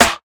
Snare (8).wav